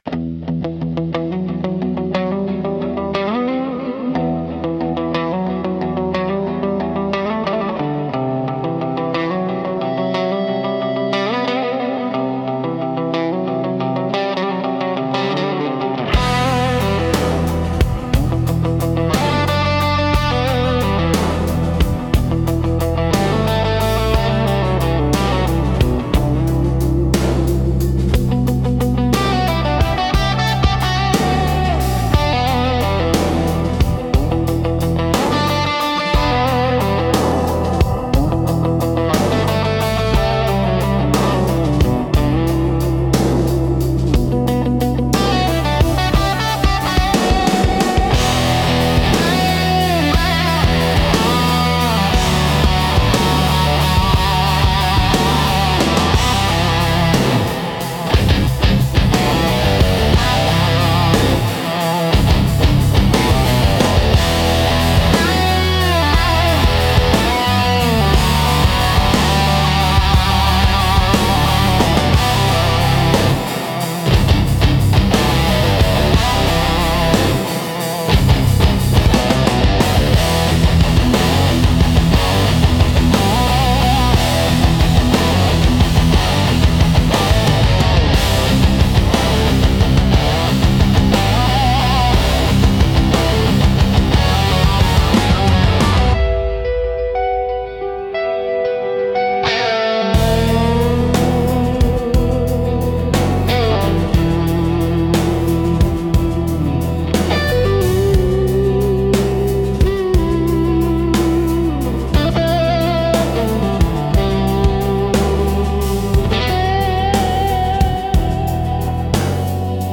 Instrumental - Ghosts in the Machine Head 4.10